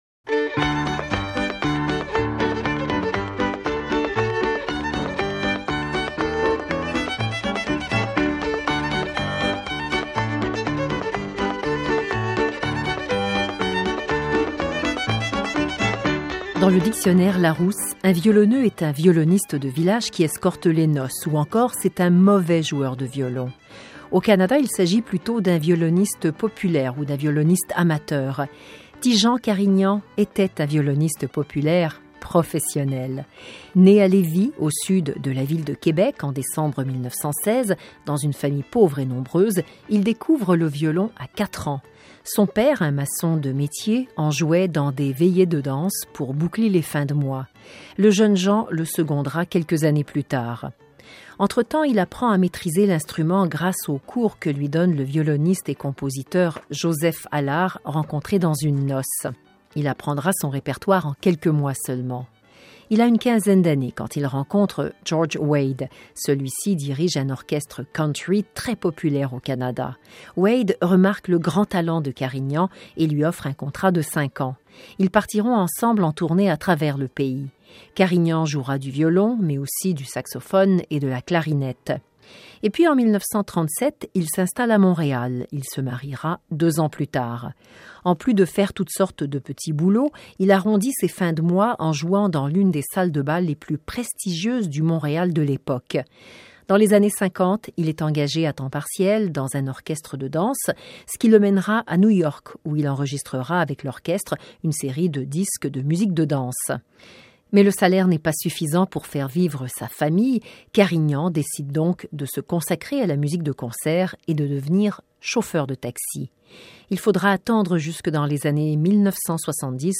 Portrait d’un virtuose dans le monde de la musique traditionnelle :